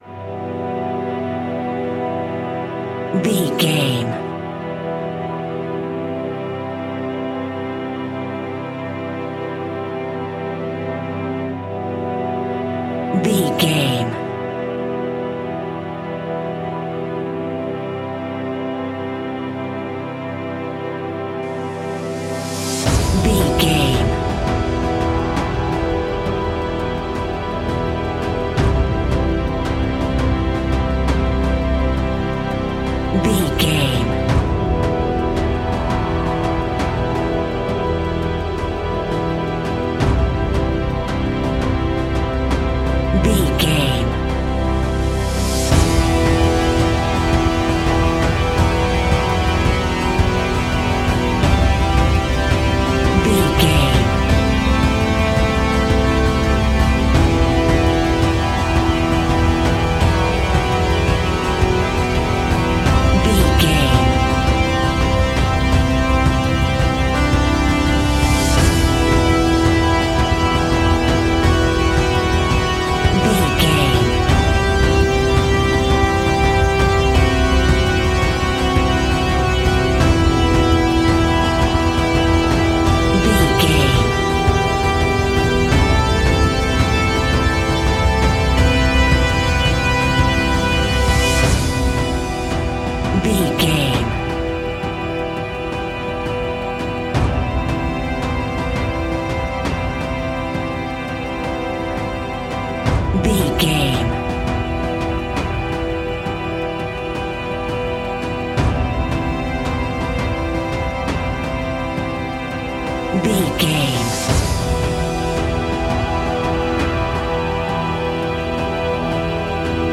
Epic / Action
Fast paced
In-crescendo
Aeolian/Minor
strings
brass
percussion
synthesiser